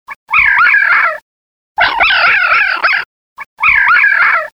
Red Fox
Voice
The most commonly heard red fox vocalizations are a quick series of barks, and a scream-like variation on a howl. Their barks are very high pitched and sound like ow-wow-wow-wow. It is commonly mistaken for an owl hooting.
red-fox-call.mp3